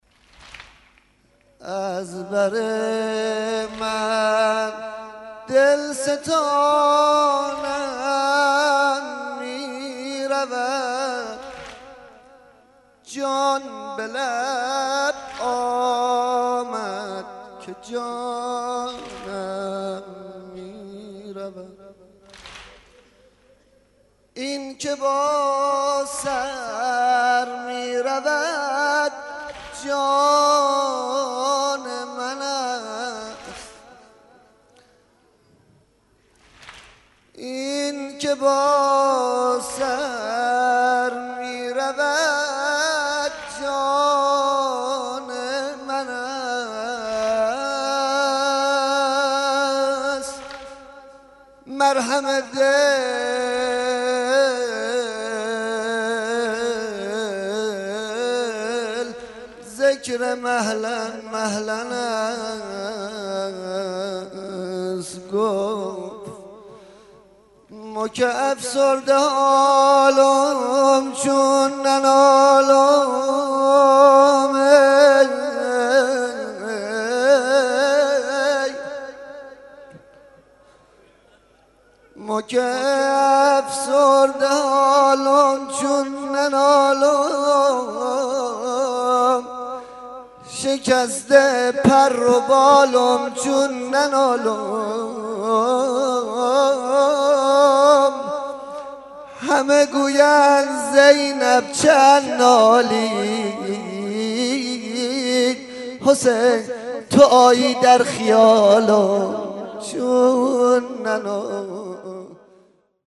شب سوم رمضان 95